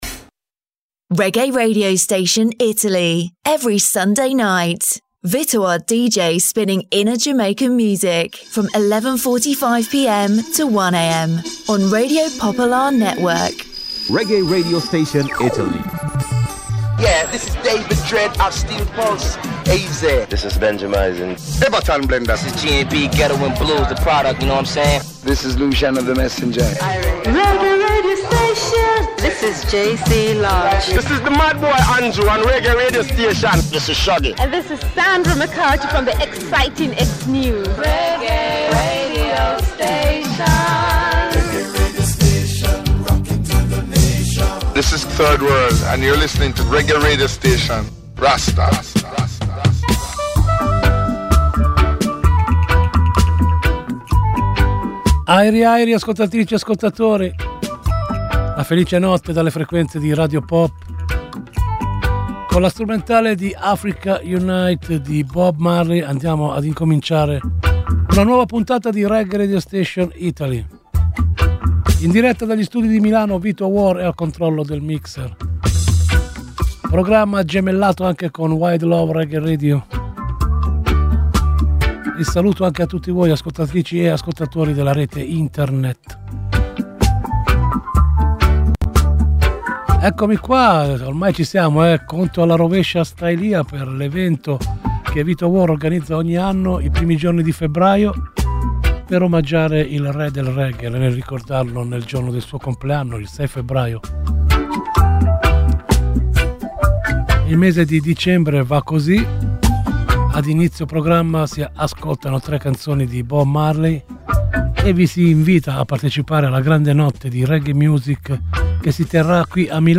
A ritmo di Reggae